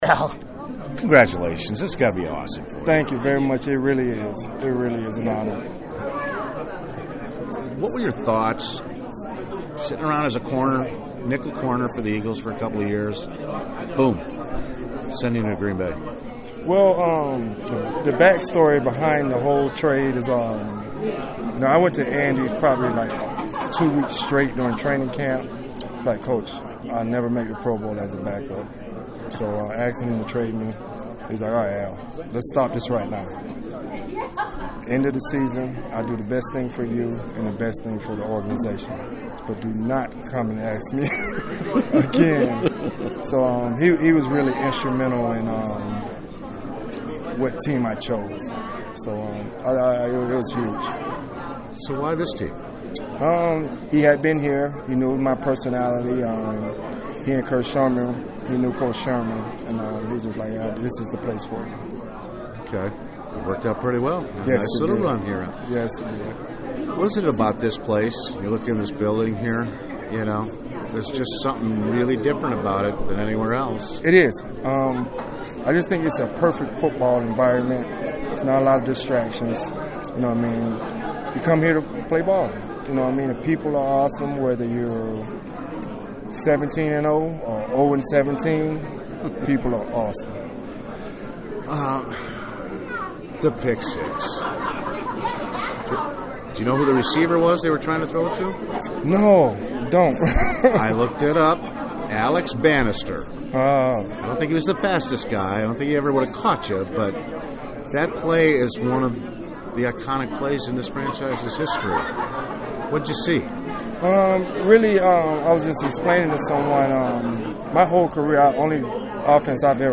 At the Hall of Fame’s bronze football unveiling ceremony Wednesday night,  I caught with Harris to talk about the honor of entering the hall.